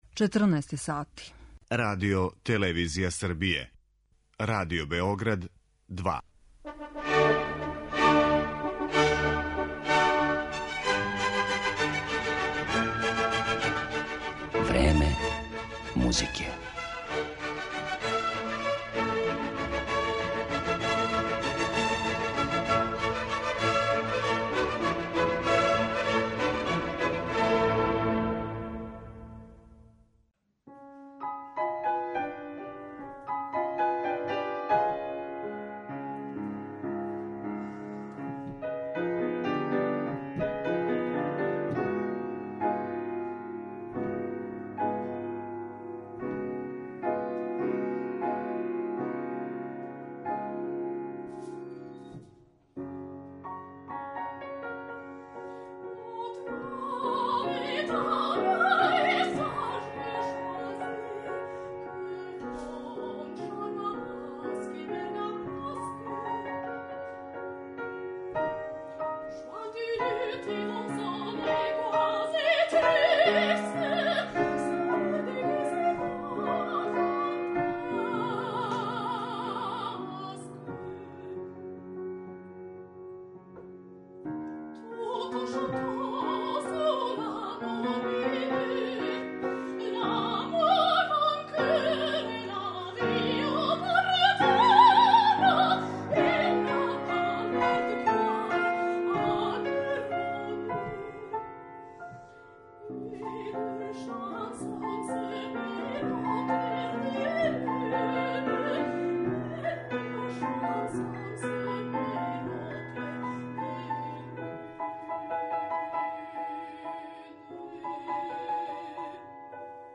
Са фестивала 'Тејковер'